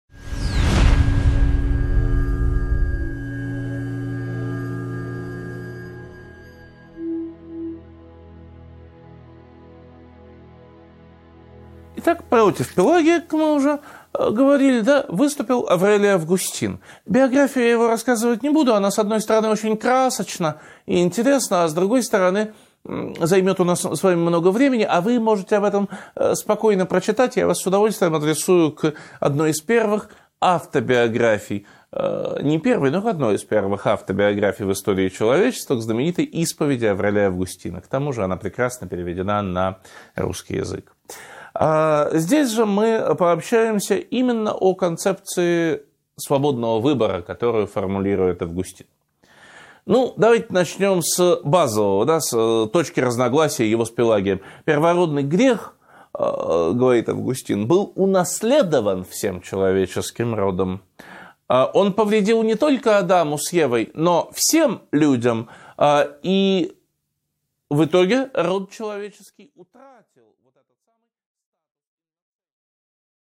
Аудиокнига 8.6 Августин vs Пелагий: полемика о свободе (продолжение) | Библиотека аудиокниг